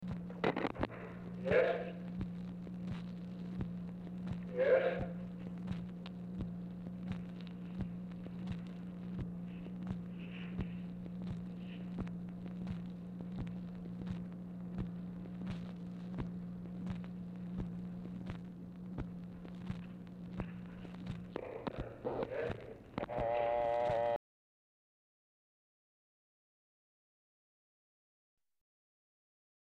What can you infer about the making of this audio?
Format Dictation belt Location Of Speaker 1 Oval Office or unknown location